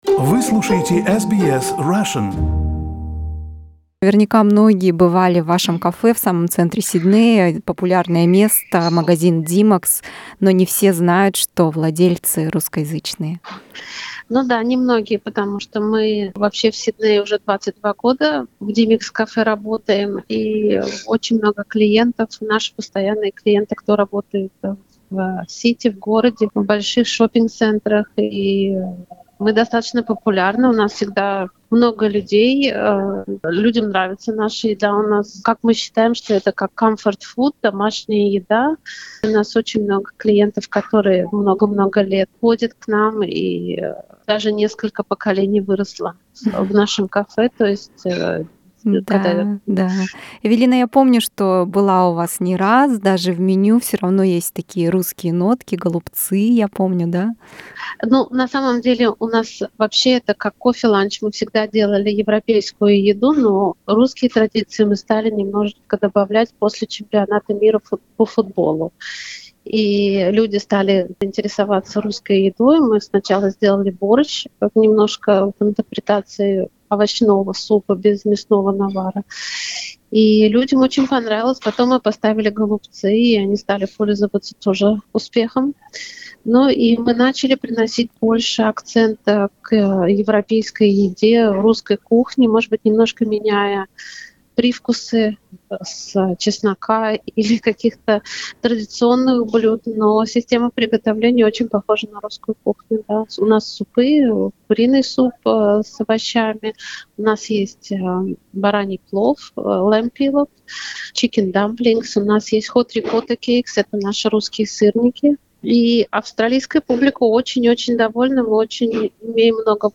Это интервью мы записывали до объявления мер по закрытию кафе и ресторанов из-за коронавируса.